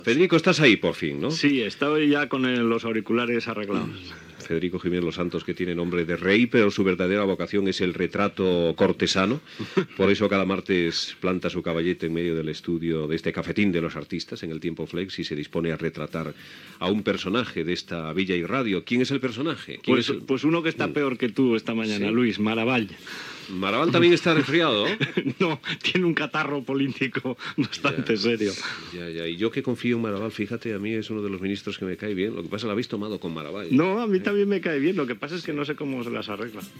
Conversa amb el col·laborador Federico Jiménez Losantos, dins "El cafetín de los artistas".
Info-entreteniment